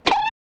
poke.ogg